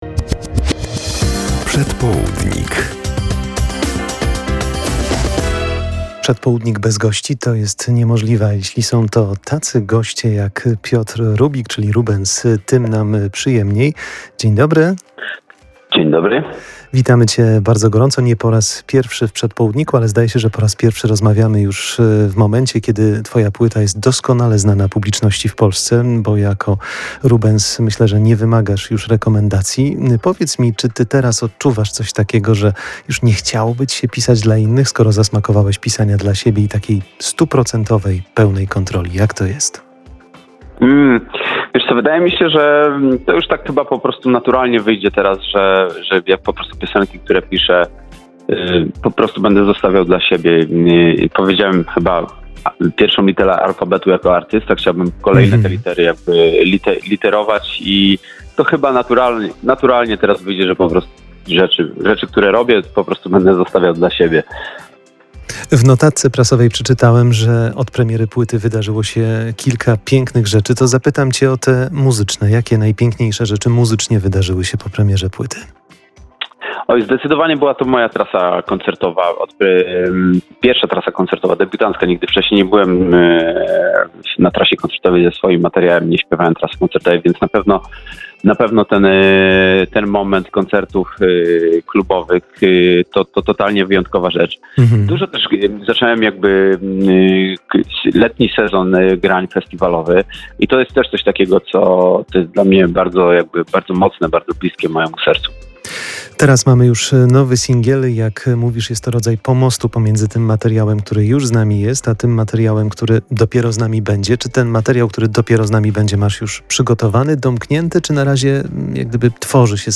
koncerty i winyl [POSŁUCHAJ ROZMOWY]